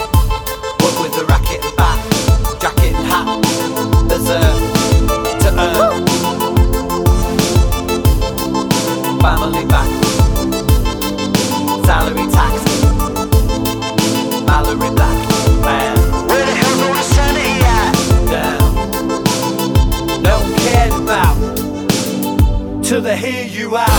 Rap Parts Only R'n'B / Hip Hop 3:35 Buy £1.50